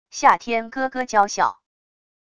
夏天咯咯娇笑wav音频